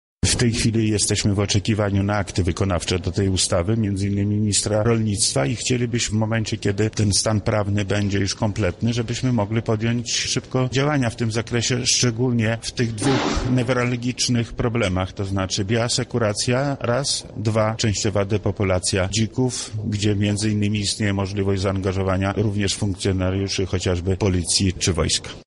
O tym jak ma przebiegać wprowadzanie ustawy w życie w naszym regionie mówi Wojewoda Lubelski, Lech Sprawka.